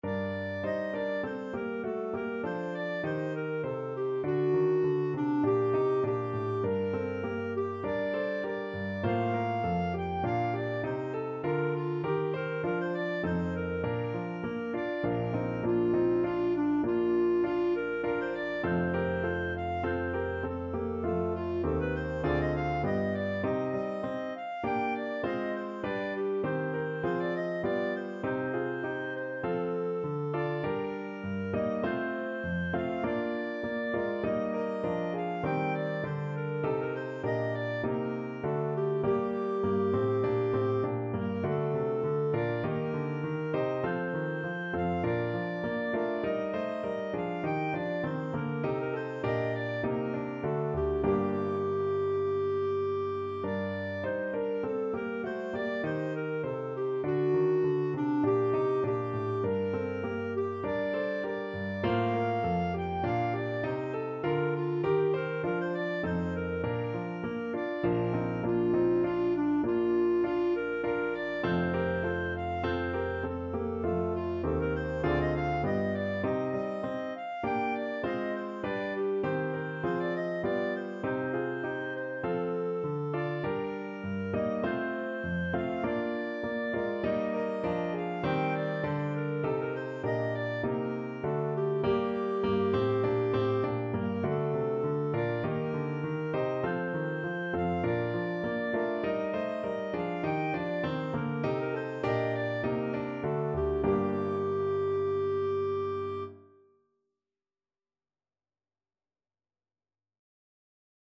Free Sheet music for Clarinet
Clarinet
Bb major (Sounding Pitch) C major (Clarinet in Bb) (View more Bb major Music for Clarinet )
4/4 (View more 4/4 Music)
Classical (View more Classical Clarinet Music)